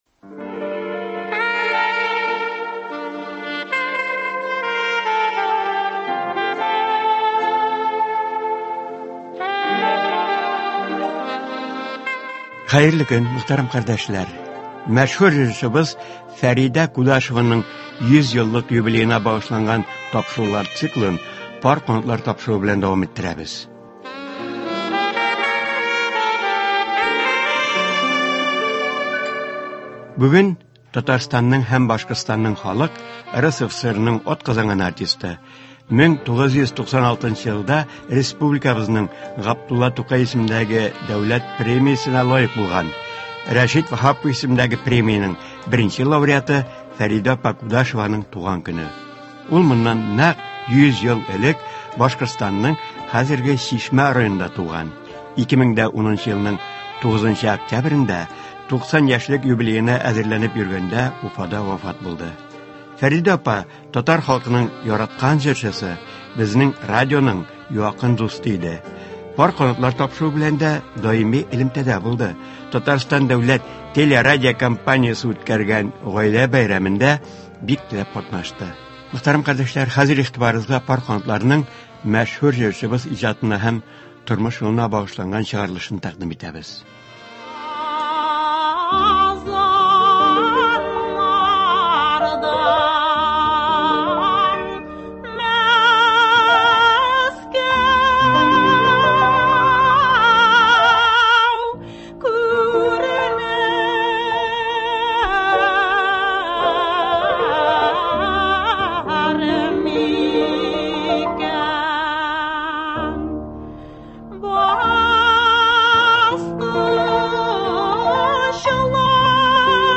Мәшһүр җырчыбыз, Татарстанның һәм Башкортстанның халык, РСФСРның атказанган артисты, Татарстанның Г.Тукай исемендәге премиясе лауреаты Фәридә Кудашеваның тууына 100 ел тулу уңаеннан Татарстан радиосы махсус тапшырулар әзерләде. “Пар канатлар” тапшыруында Фәридә Кудашева иҗатына багышлап, җырчының үзе катнашында моннан берничә ел элек әзерләнгән тапшыру кабатлап бирелә.